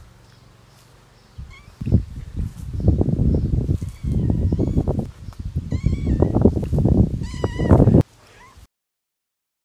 I was out recording a few things and one of my cats ran up to me and wanted to talk.
In binaural audio! Might want to wear headphones for the best effect.